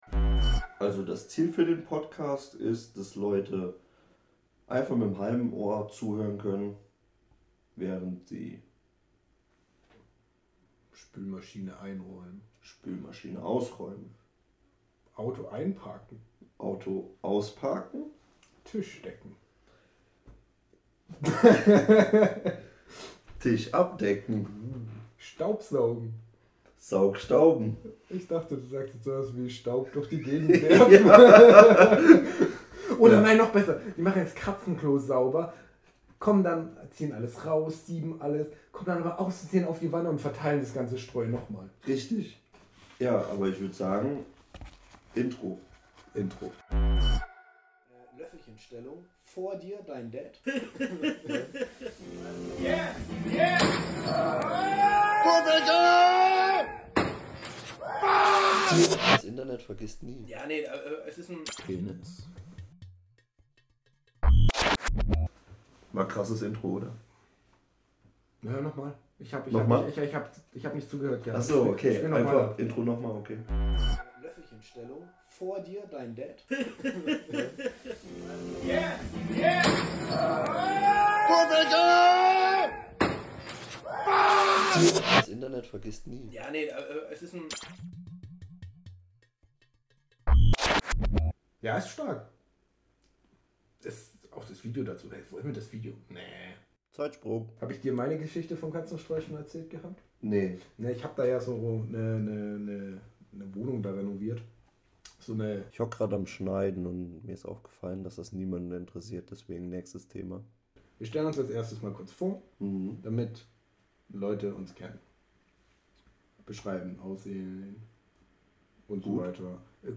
Bereitet eure Ohren auf einen ungefilterten, unbearbeiteten und unvorbereiteten Podcast vor.